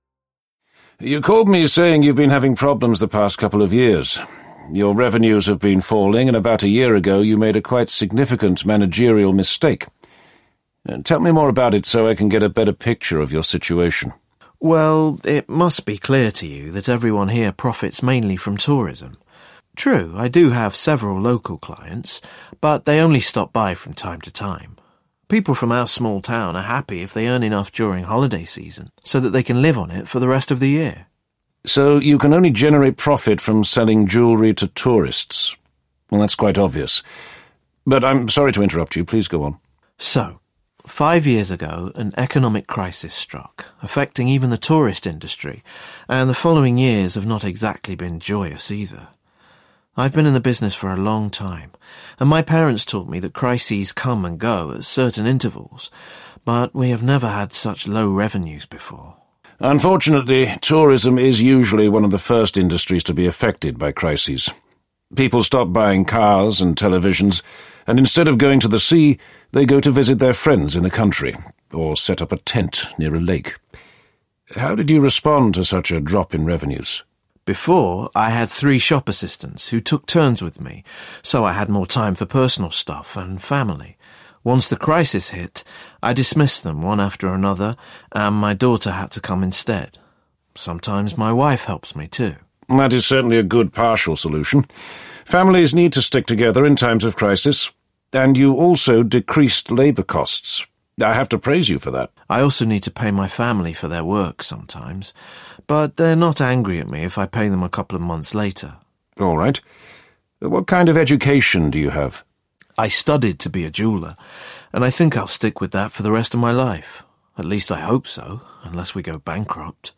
Business Risk Buster Intervenes 2 audiokniha
Ukázka z knihy